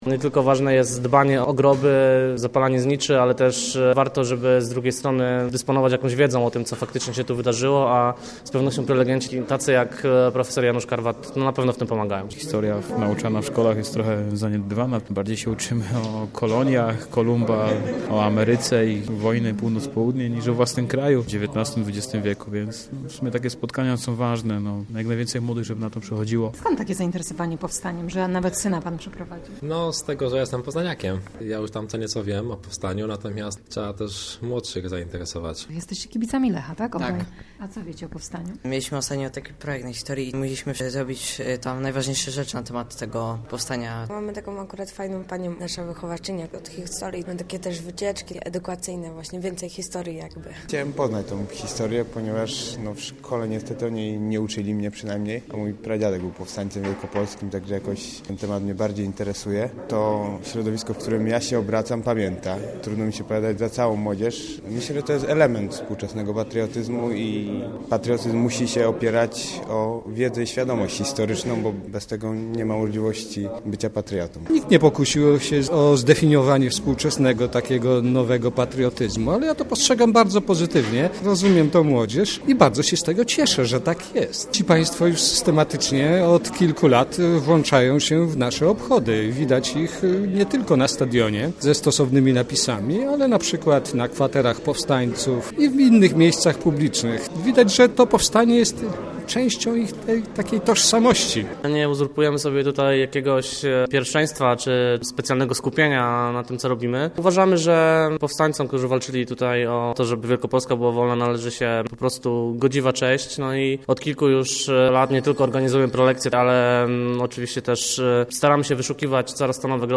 Debatę zorganizował Kibolski Klub Dyskusyjny.